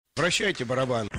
Звук с Якубовичем и фразой Вращайте барабан